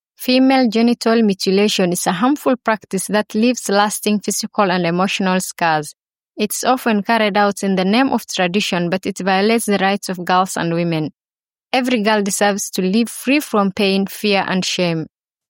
Commercial Demo
I’m a native Somali and Swahili voice-over artist with a warm, clear, and professional voice.
HighLowMezzo-Soprano
Warm
All our voice actors record in their professional broadcast-quality home studios using high-end microphones.